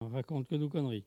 Mots Clé parole, oralité
Catégorie Locution